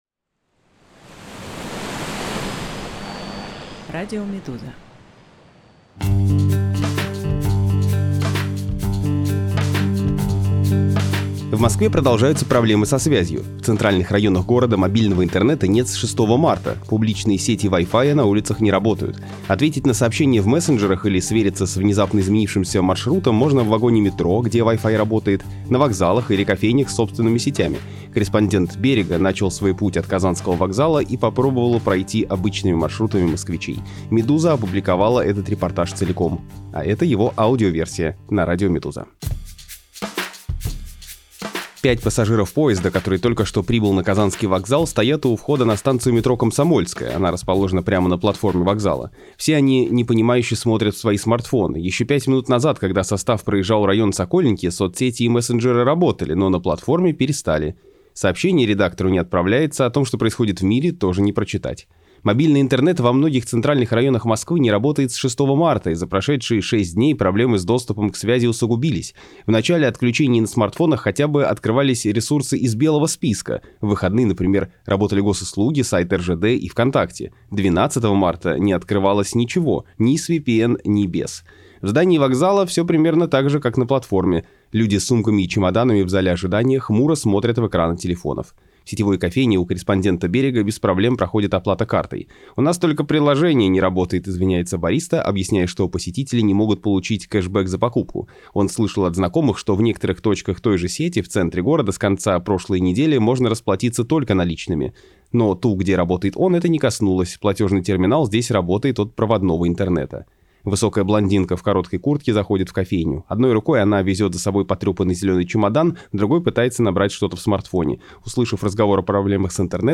Москва без интернета. Как горожане ищут связь? Аудиоверсия репортажа из центра города